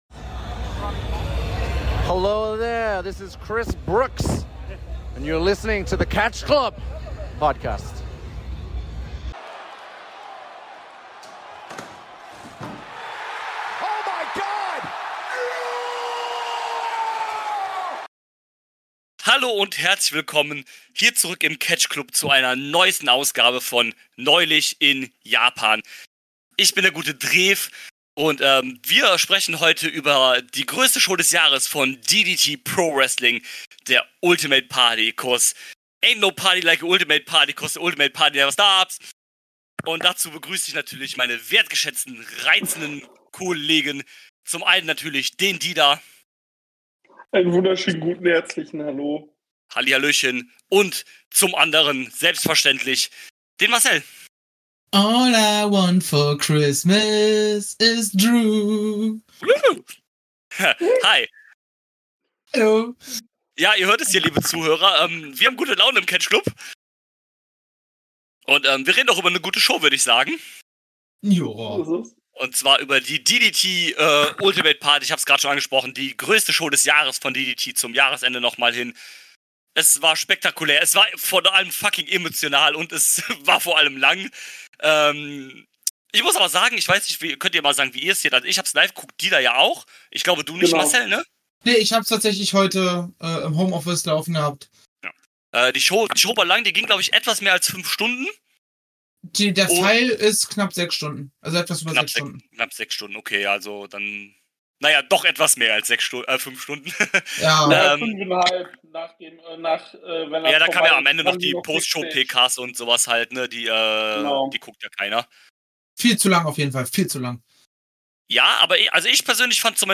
DDT hat zur Party des Jahres eingeladen. Zu dritt sprechen wir über Ultimate Party.